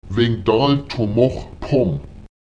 Die Imperativausdrücke -pom, -ral und -sóm können hervorgehoben werden, indem man sie anstatt ihrer grammatikalisch korrekten Position als Verbsuffixe im Prädikat nach einem Lith () am Ende des Satzes anbringt und sie kräftiger artikuliert: